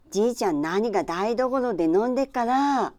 Aizu Dialect Database
Type: Statement
Final intonation: Falling
Location: Aizuwakamatsu/会津若松市
Sex: Female